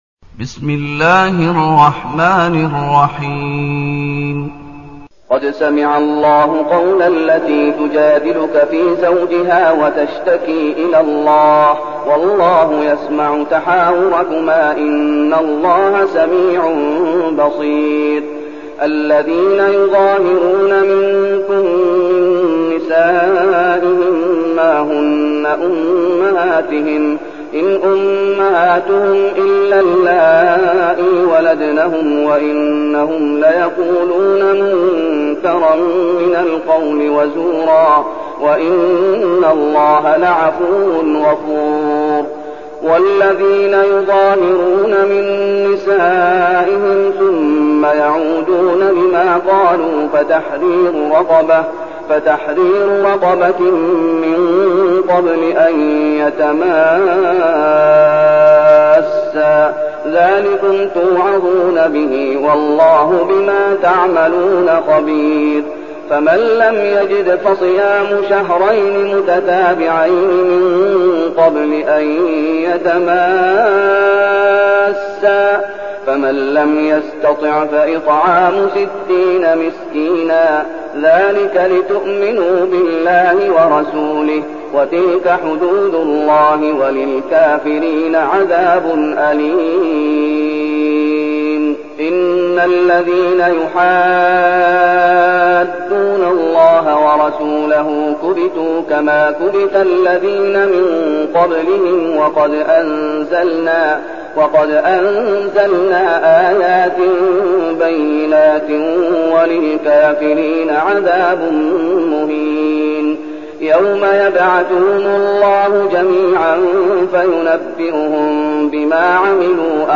المكان: المسجد النبوي الشيخ: فضيلة الشيخ محمد أيوب فضيلة الشيخ محمد أيوب المجادلة